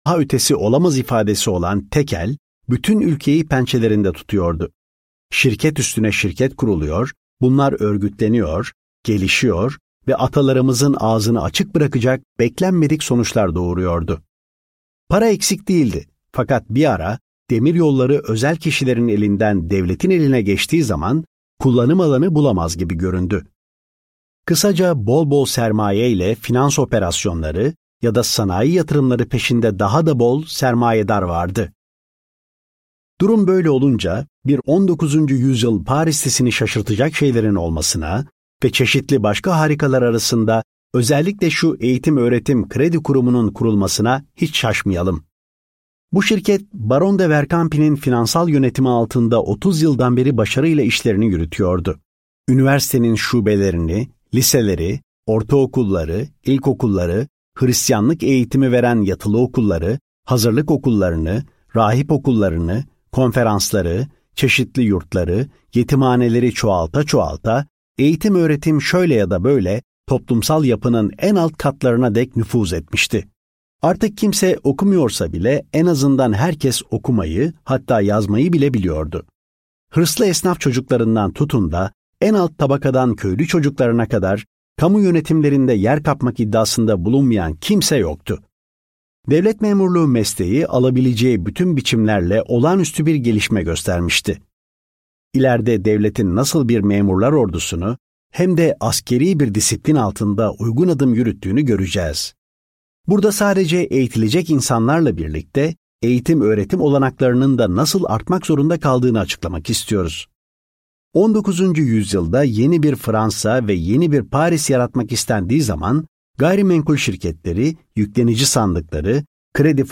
Yirminci Yüzyılda Paris - Seslenen Kitap